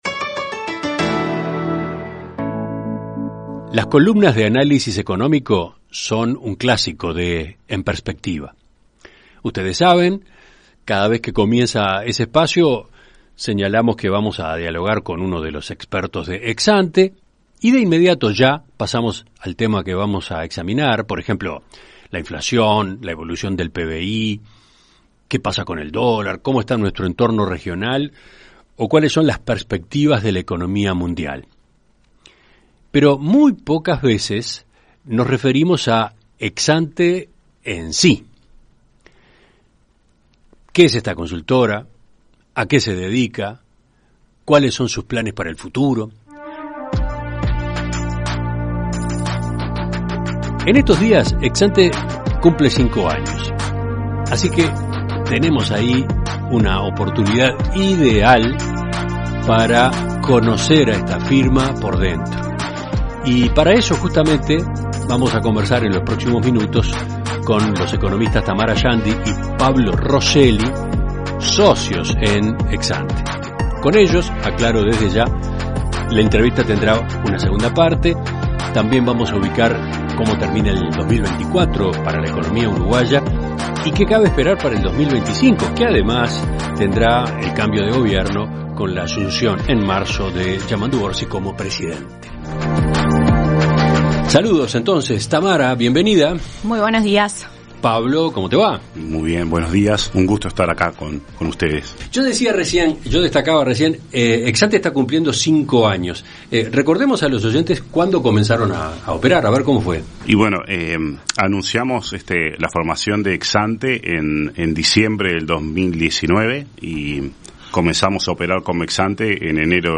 En Perspectiva Zona 1 – Entrevista Central: Consultora Exante - Océano